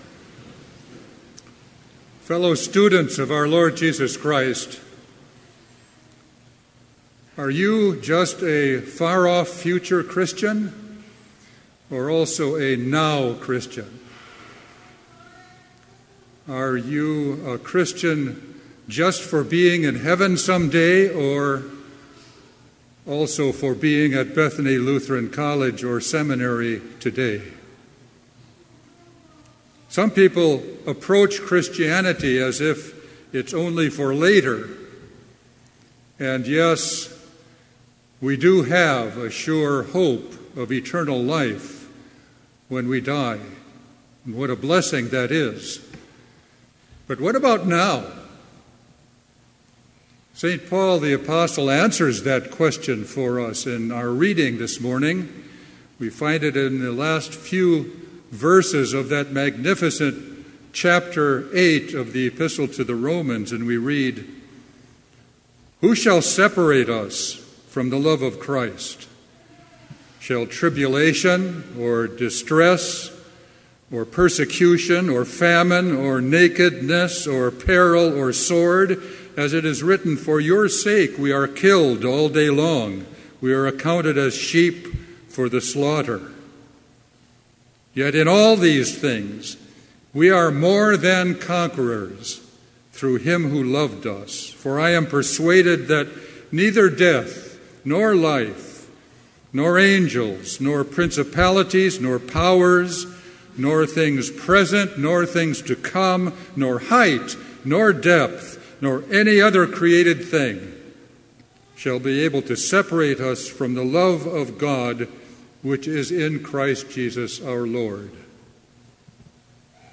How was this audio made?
This Chapel Service was held in Trinity Chapel at Bethany Lutheran College on Wednesday, September 12, 2018, at 10 a.m. Page and hymn numbers are from the Evangelical Lutheran Hymnary.